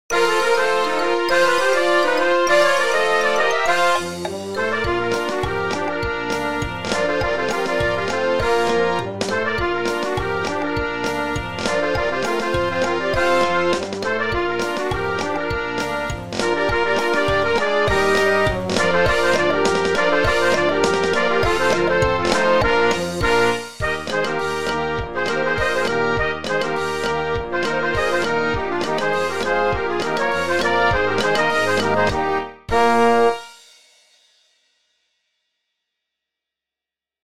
pour les Harmonies
ragtime